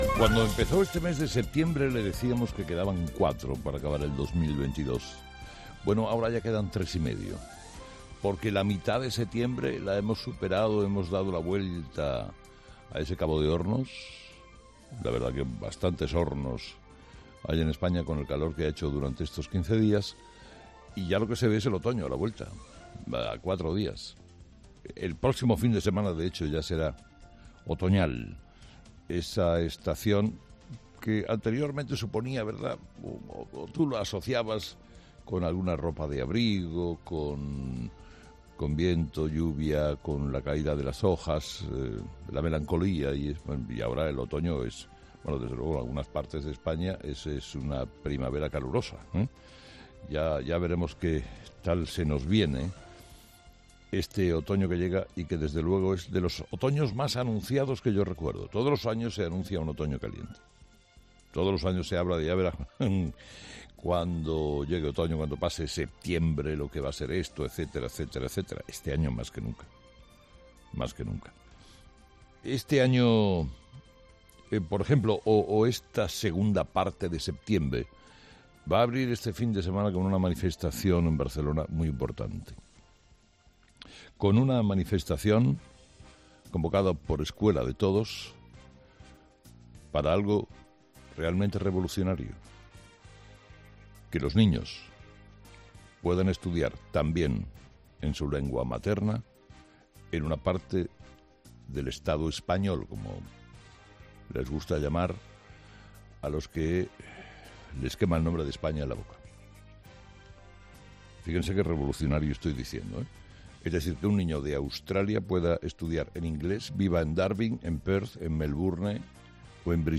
Carlos Herrera, director y presentador de 'Herrera en COPE', ha comenzado el programa de este viernes analizando las principales claves de la jornada, que pasan, entre otros asuntos, por la reunión de los vocales del CGPJ y el último acto de Sánchez.
Por otro lado, ha recordado, en tono de ironía, las noticias en torno a los nombramientos que debe hacer el CGPJ: "Es un buen día para ir en el metro y agudizar el oído, seguro que hoy las conversaciones van a ser unívocas.